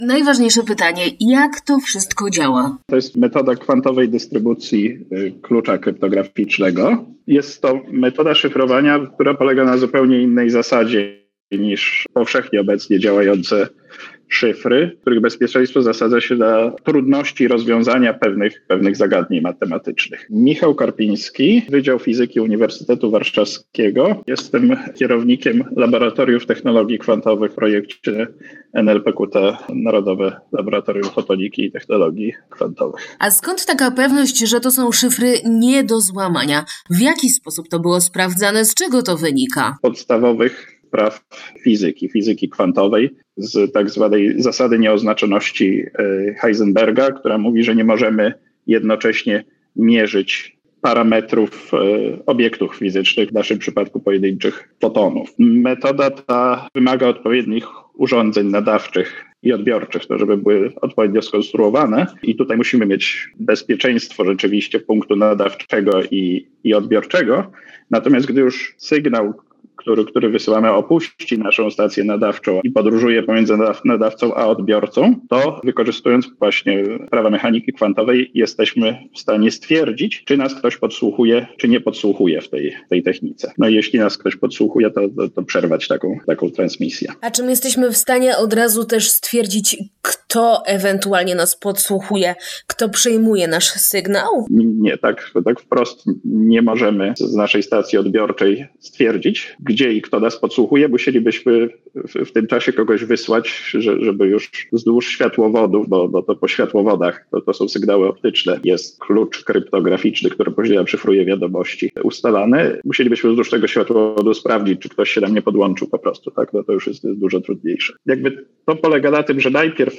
Ten ostatni był gościem programu w Radiu ESKA, w którym przybliżył szerszemu gronu odbiorców specyfikę przeprowadzonych testów.